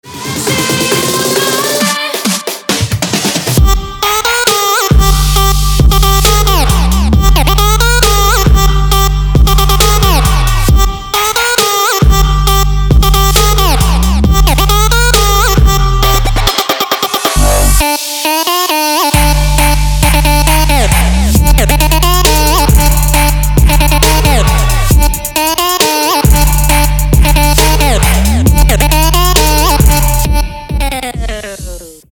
Trap
club
трэп